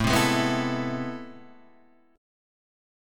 A Minor Major 9th